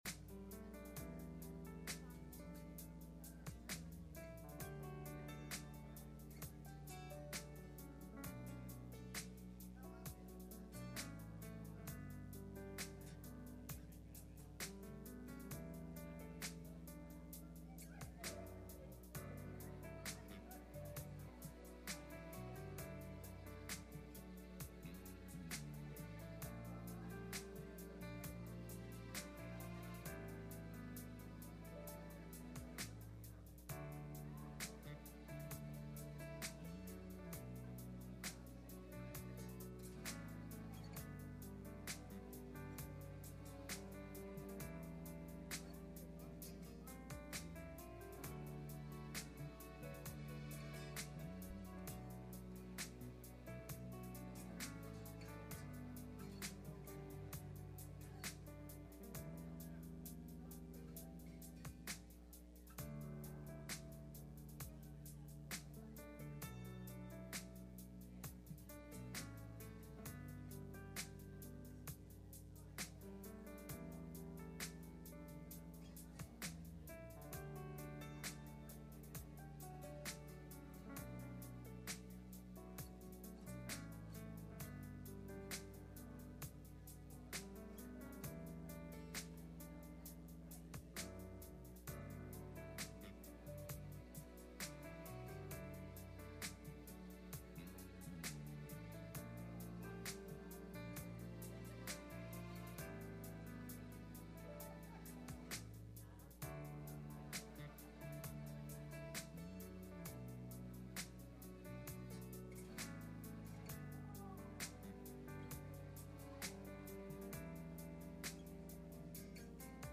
Message Service Type: Sunday Morning https